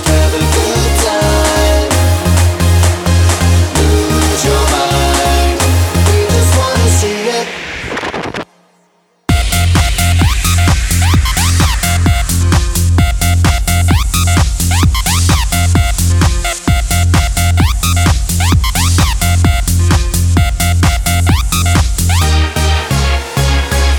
for duet R'n'B / Hip Hop 4:23 Buy £1.50